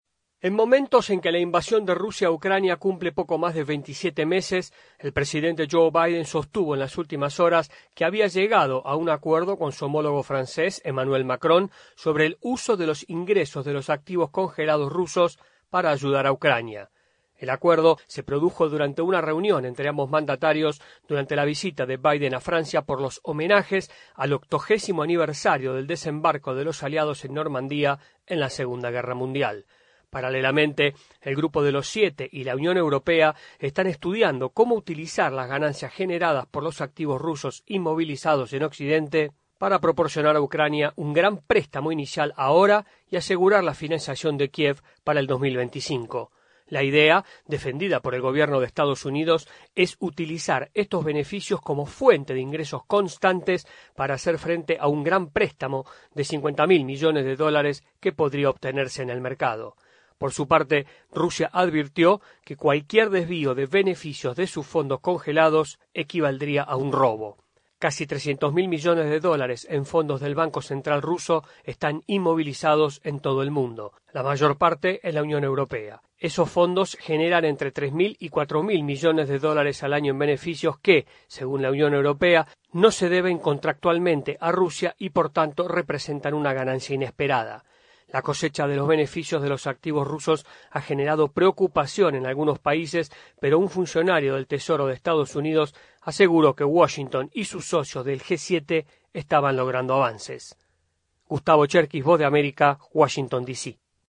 desde la Voz de América en Washington DC